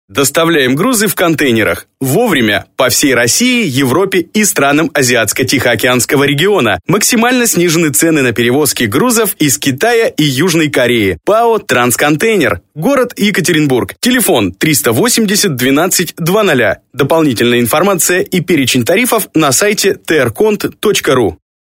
Актёр.
микрофоны: Audio-Technica AT 4050 предусилители: TL-Audio Dual Valve Mic Preamp/DI конверторы: Digidesign Digi 001